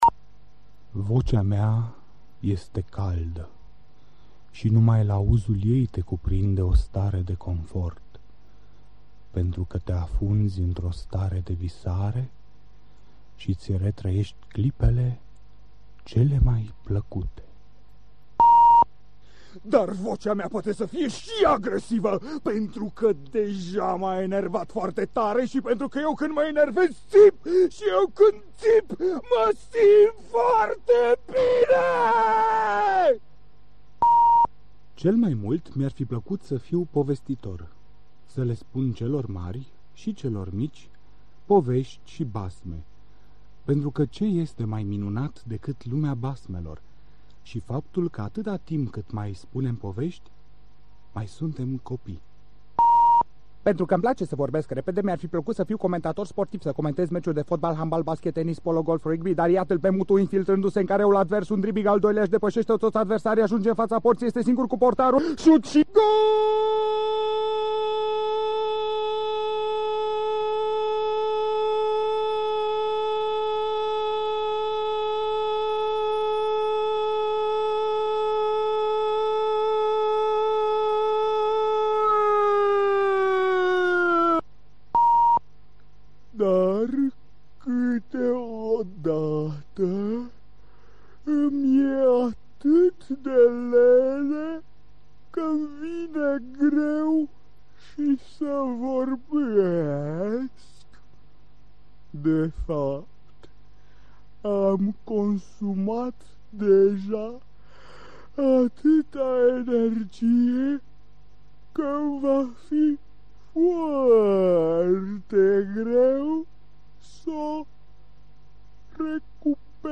Language Reels Romanian level: native
playing ages 45 to 60, male.